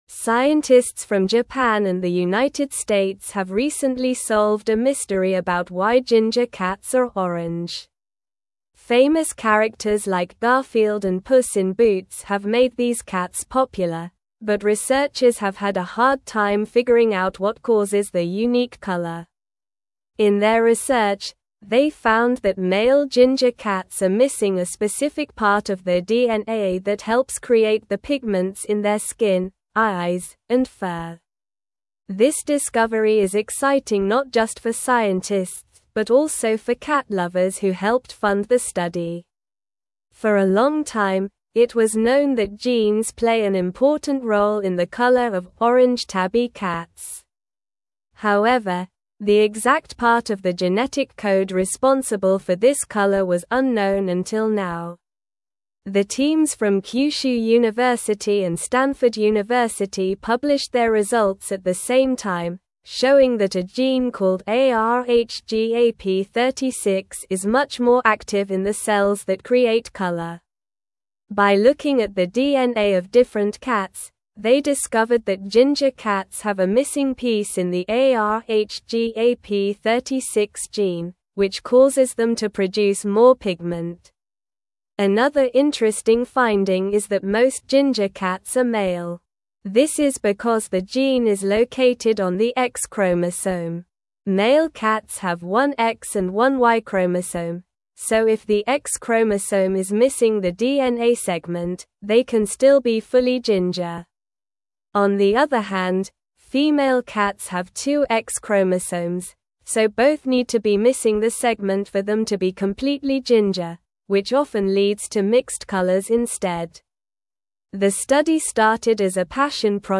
Slow
English-Newsroom-Upper-Intermediate-SLOW-Reading-Genetic-Mystery-of-Ginger-Cats-Unveiled-by-Researchers.mp3